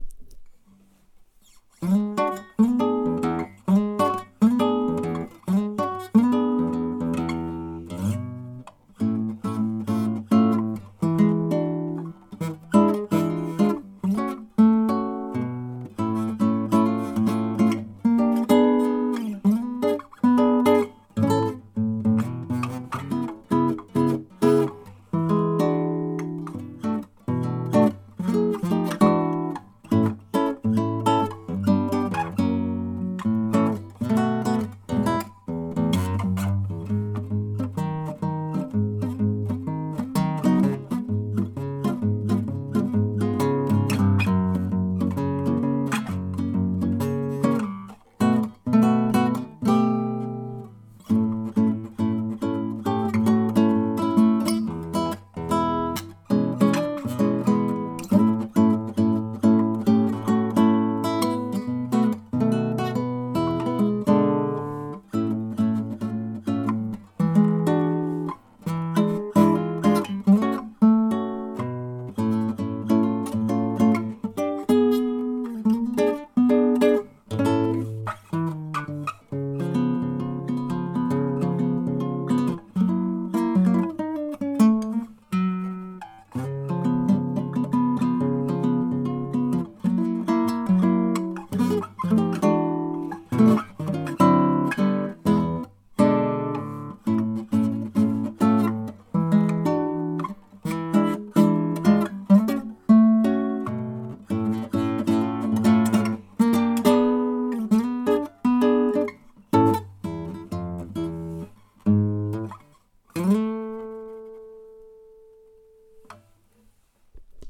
Ich spiele Gitarre und schreibe dort Musik selbst (Hörbeispiele: “